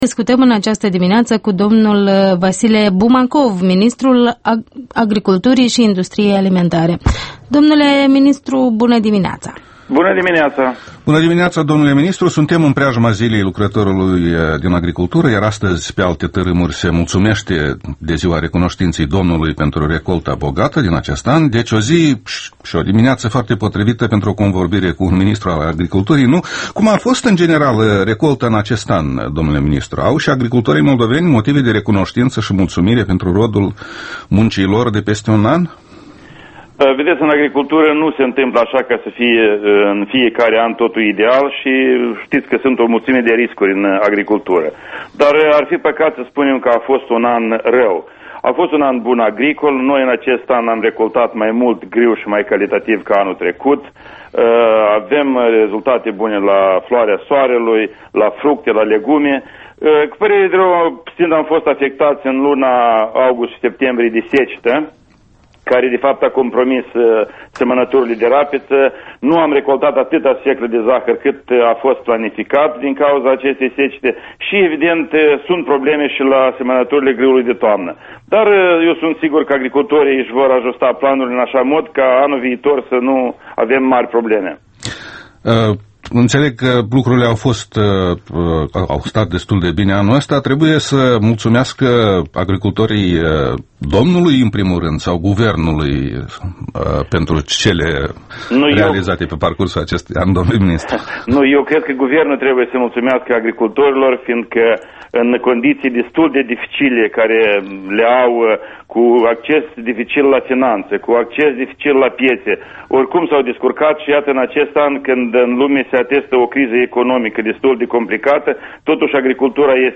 Interviul dimineții la Europa Liberă: cu ministrul Vasile Bumacov despre situația agriculturii